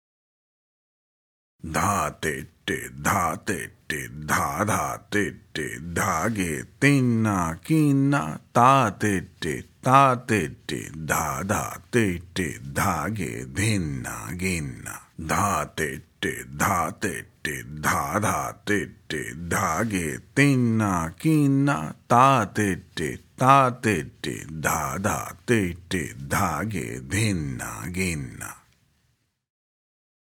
Spoken – Slow